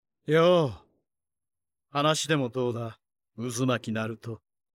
Download “obito laugh” obito-laugh.mp3 – Downloaded 7126 times – 188.73 KB